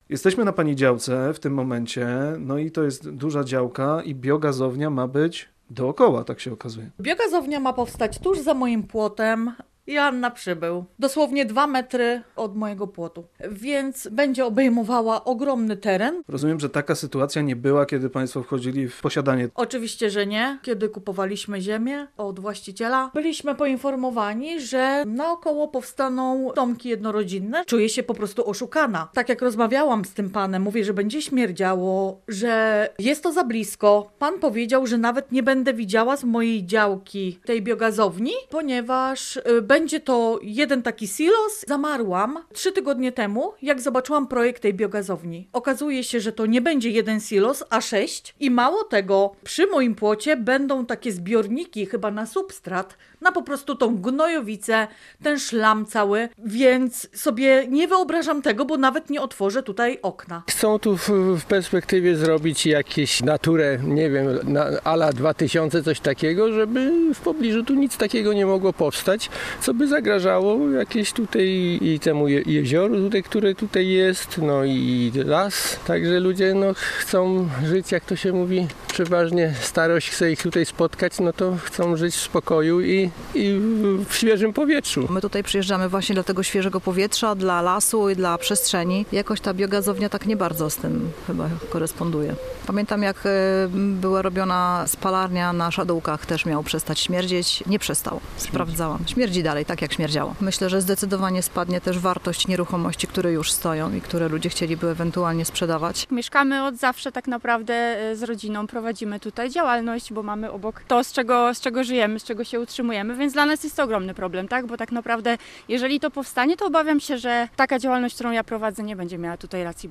– Przyjeżdżamy tu właśnie dla tego świeżego powietrza, dla lasu i przestrzeni – mówił jeden z protestujących.